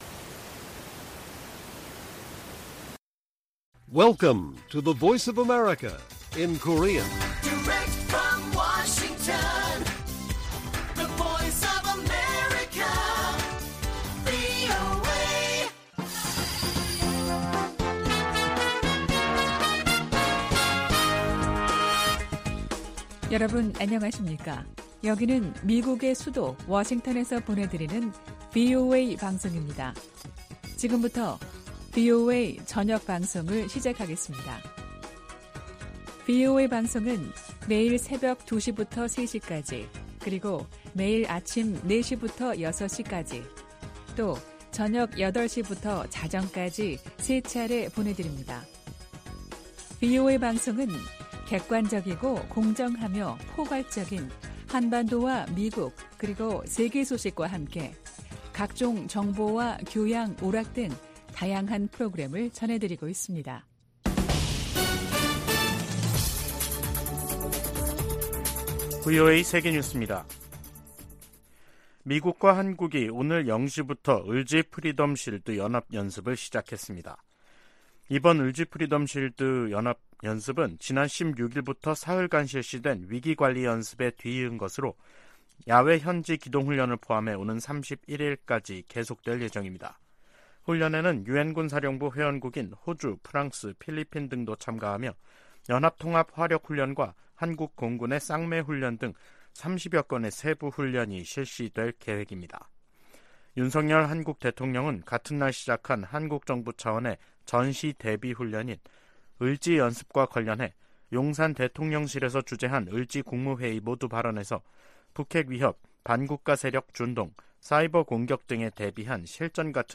VOA 한국어 간판 뉴스 프로그램 '뉴스 투데이', 2023년 8월 21일 1부 방송입니다. 미국과 한국, 일본 정상들은 18일 채택한 캠프 데이비드 정신에서 3국 정상 회의를 연 1회 이상 개최하기로 합의했습니다. 윤석열 한국 대통령은 북한의 도발 위협이 커질수록 미한일 안보 협력은 견고해질 것이라고 밝혔습니다. 조 바이든 미국 대통령은 우크라이나에서와 같은 사태가 아시아에도 벌어질 수 있다고 경고하며 안보 협력 중요성을 강조했습니다.